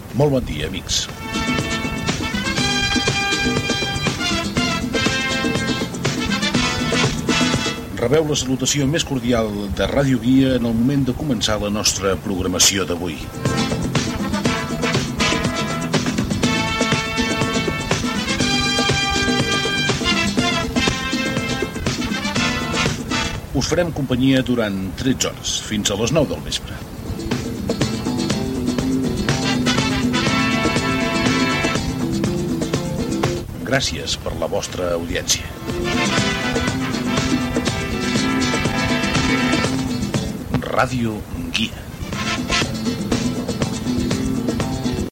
Inici de l'emissió amb la salutació i la identificació.
FM